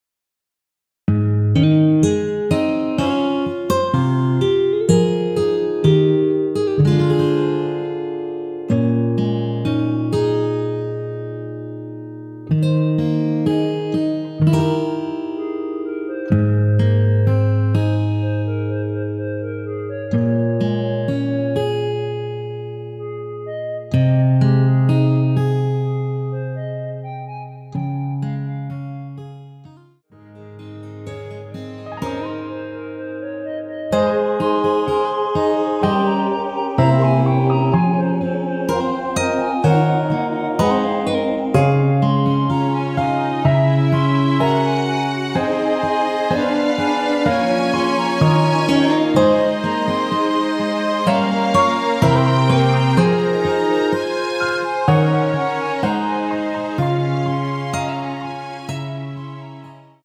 원키에서(+5)올린 멜로디 포함된 MR입니다.
Ab
멜로디 MR이라고 합니다.
앞부분30초, 뒷부분30초씩 편집해서 올려 드리고 있습니다.
중간에 음이 끈어지고 다시 나오는 이유는